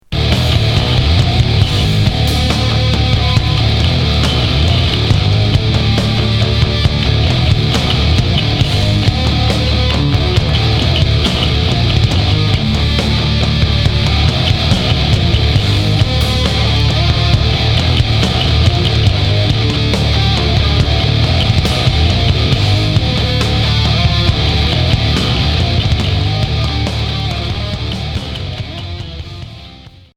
Noise Quatrième 45t